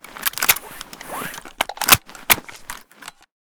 m4a1_reload.ogg